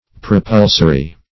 Propulsory \Pro*pul"so*ry\